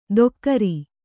♪ dokkari